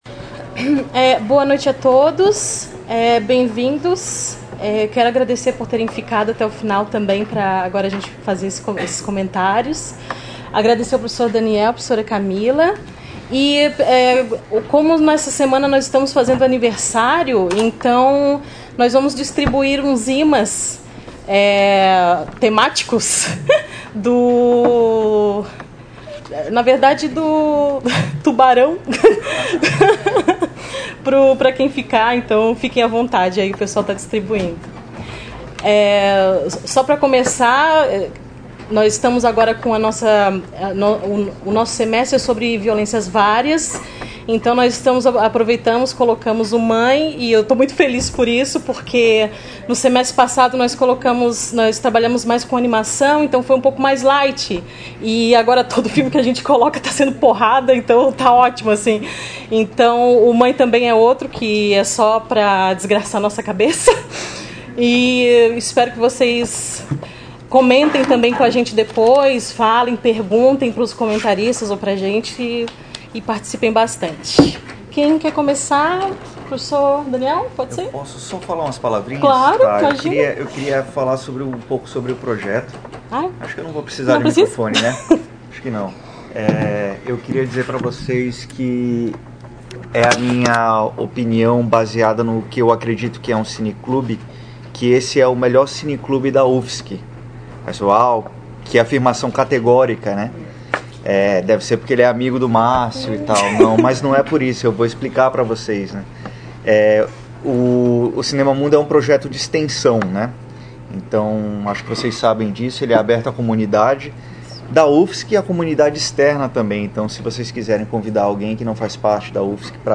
Comentários dos debatedore(a)s convidado(a)s